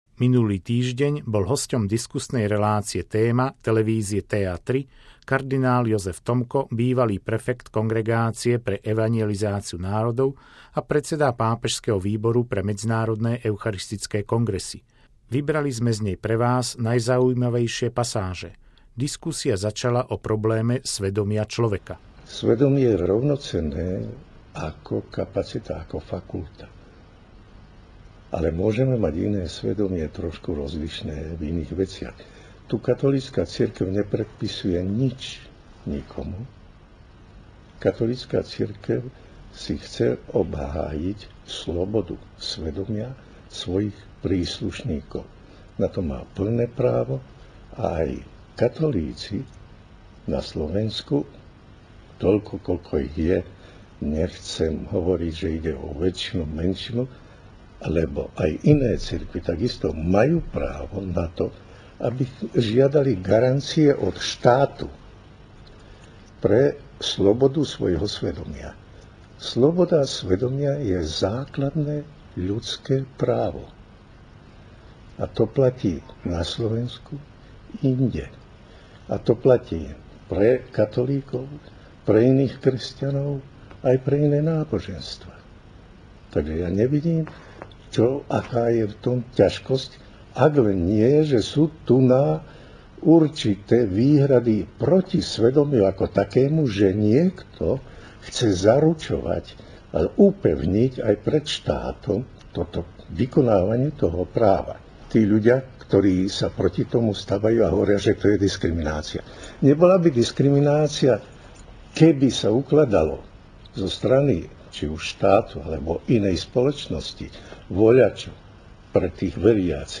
Slovensko: Minulý týždeň bol v hosťom diskusnej relácie "Téma dňa" televízie TA3 kardinál Jozef Tomko, bývalý prefekt Kongregácie pre evanjelizáciu národov a predseda Pápežského výboru pre medzinárodné eucharistické kongresy. Vybrali sme z nej vás najzaujímavejšie pasáže.